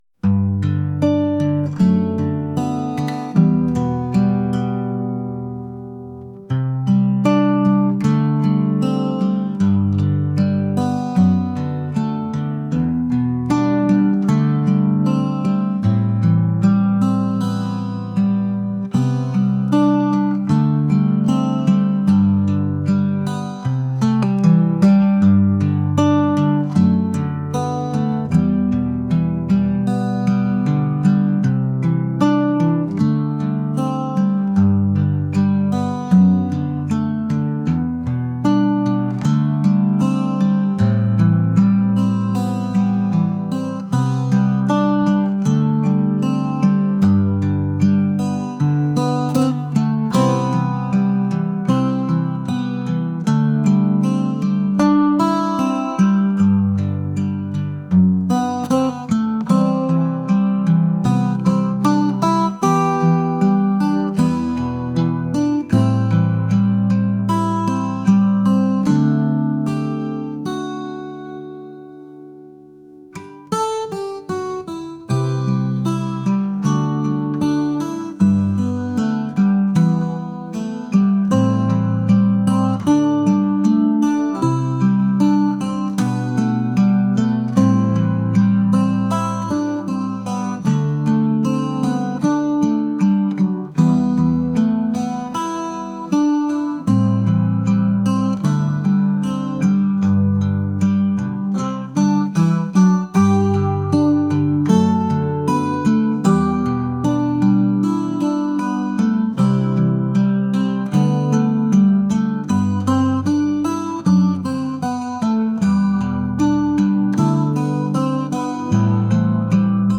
folk | acoustic | indie